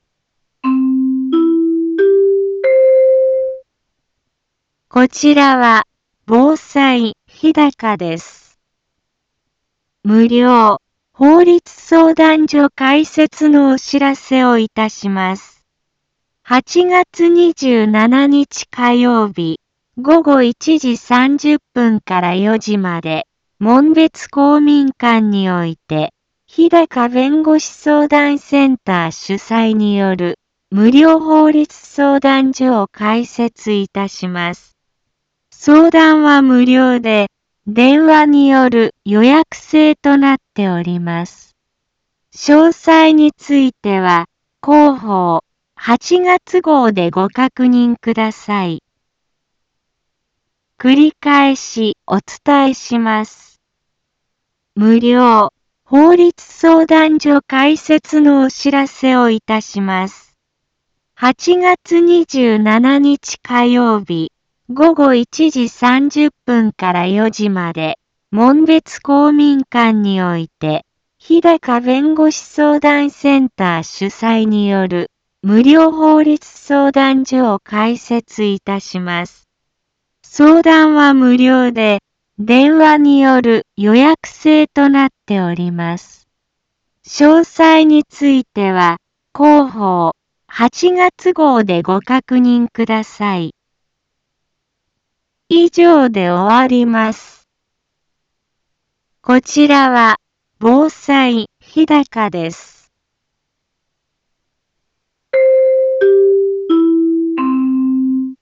Back Home 一般放送情報 音声放送 再生 一般放送情報 登録日時：2024-08-20 15:03:48 タイトル：無料法律相談会のお知らせ インフォメーション： 無料法律相談所開設のお知らせをいたします。 8月27日火曜日午後1時30分から4時まで、門別公民館において、ひだか弁護士相談センター主催による、無料法律相談所を開設いたします。